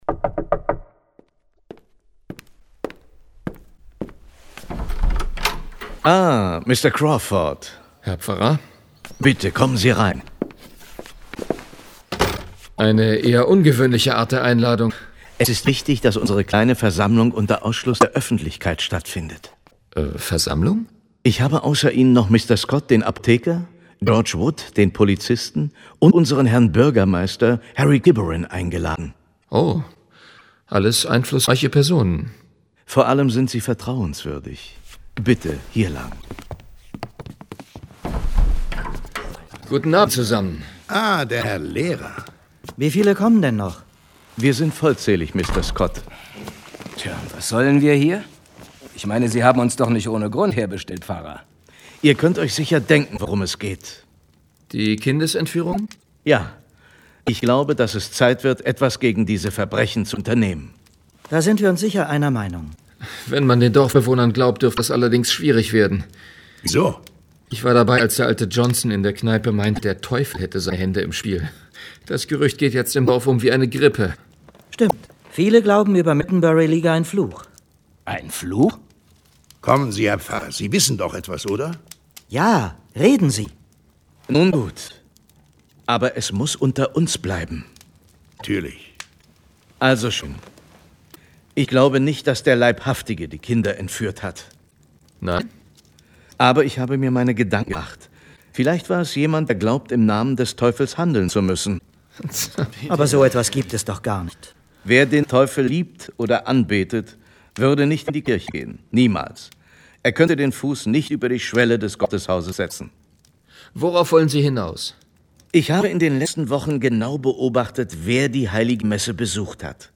John Sinclair - Folge 45 Die Teufelsuhr. Hörspiel.